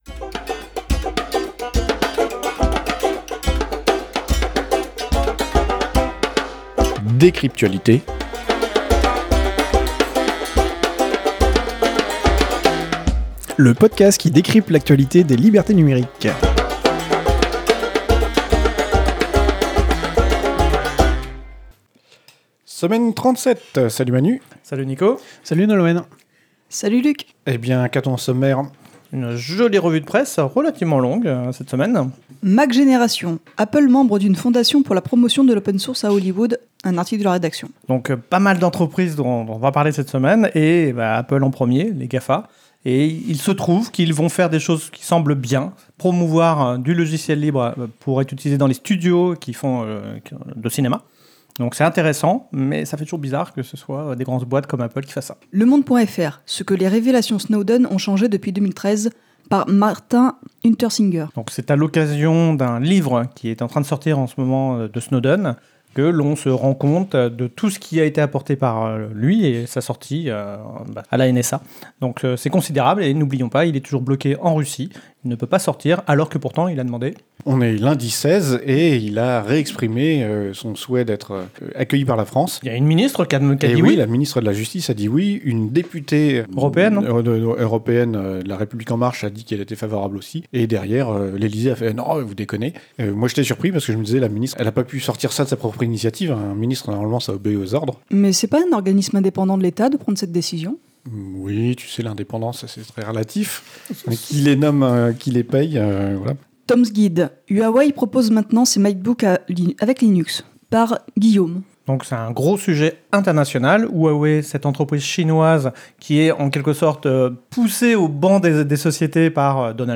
Lieu : April - Studio d'enregistrement
Revue de presse pour la semaine 37 de l'année 2019